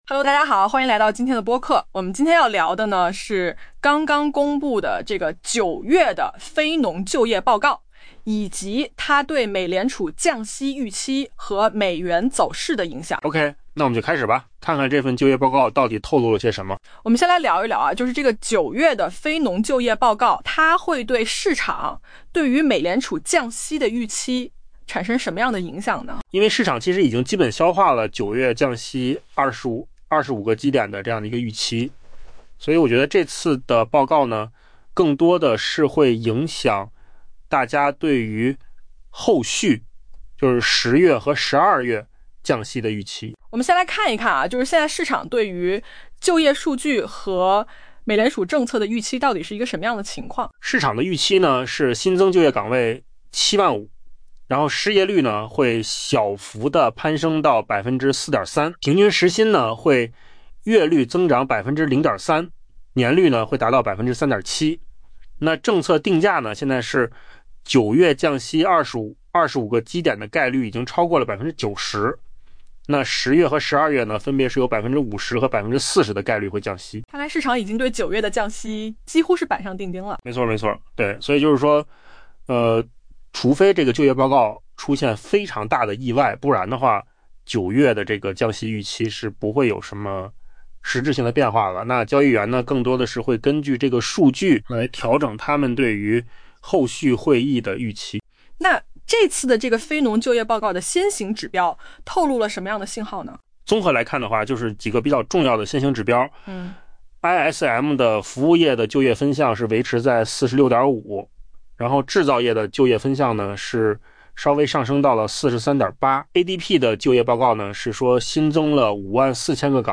AI 播客：换个方式听新闻
音频由扣子空间生成